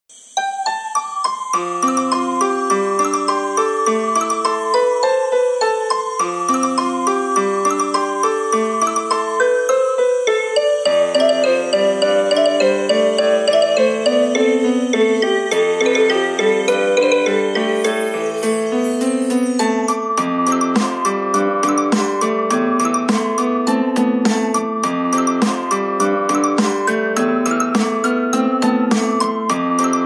Categoría Pop